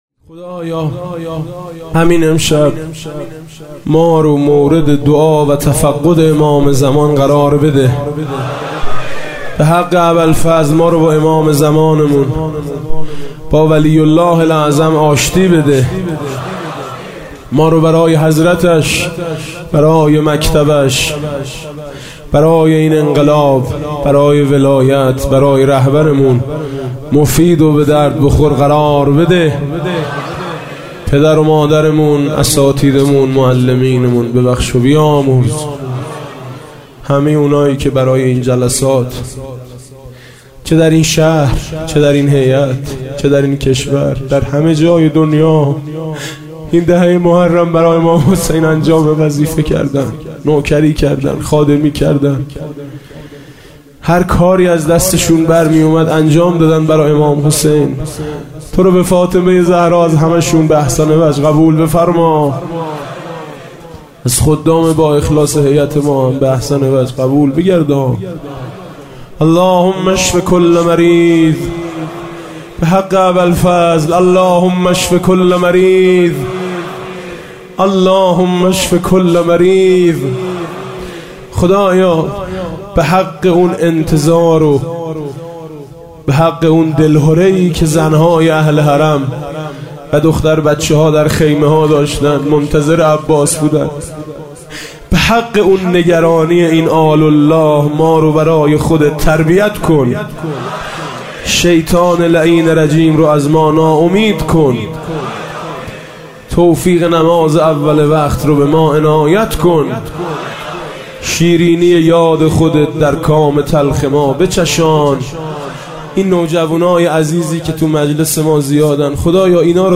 دانلود بخش ۱۴ – خدایا به حق اباالفضل – دعای پایانی مراسم